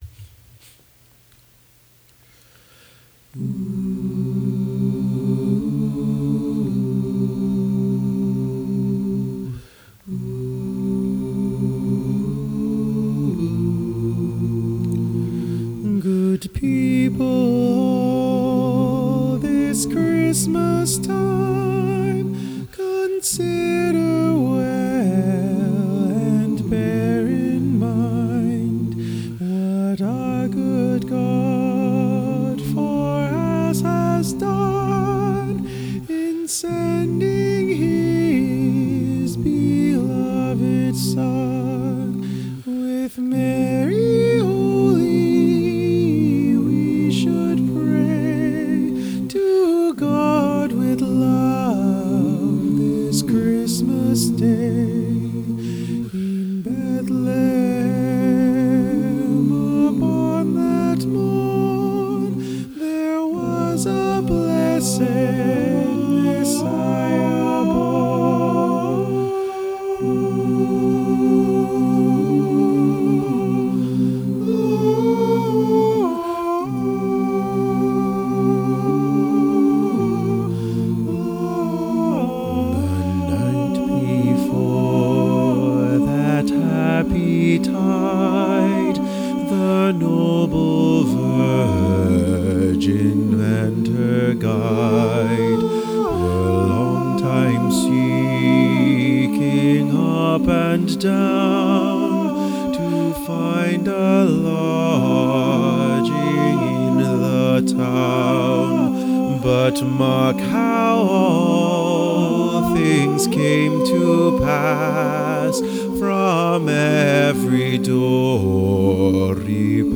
All works acappella unless otherwise specified.
SATB - Christmas Arrangements
Wexford Carol (SATB) - sheet music -
The Wexford Carol SATB.mp3